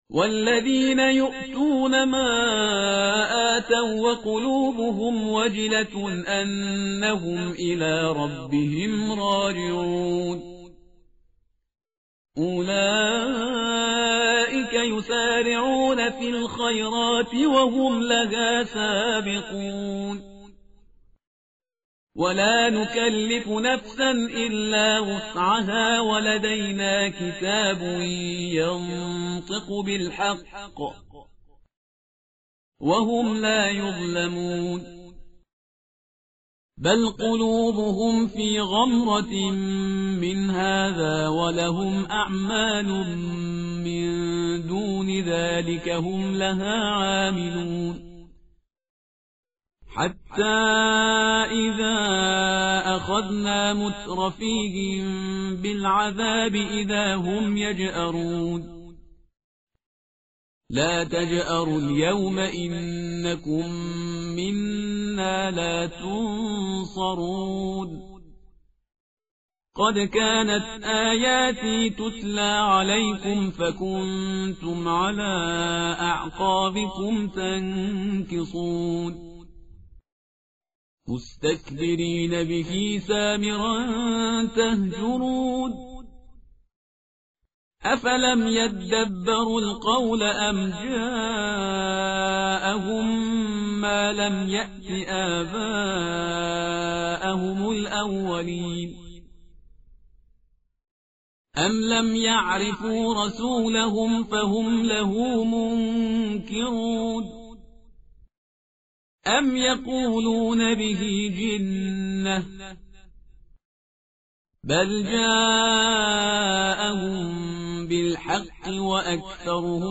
متن قرآن همراه باتلاوت قرآن و ترجمه
tartil_parhizgar_page_346.mp3